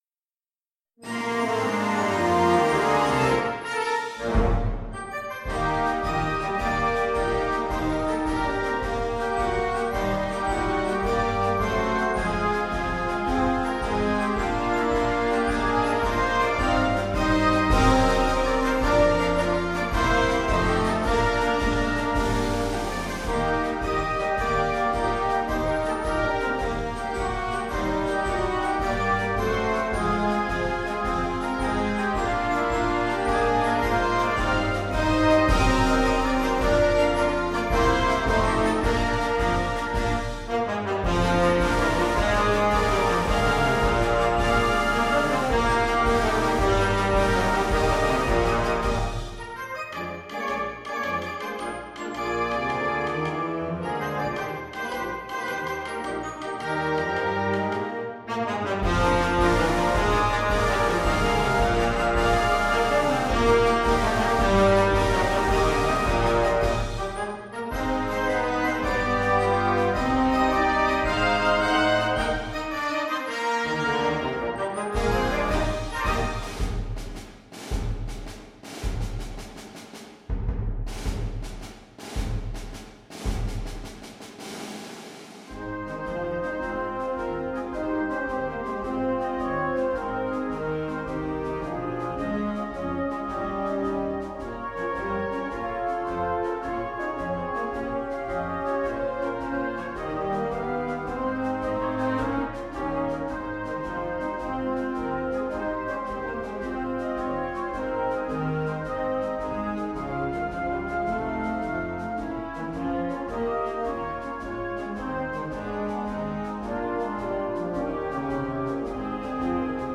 Konzertmarsch für Blasorchester Schwierigkeit
Besetzung: Blasorchester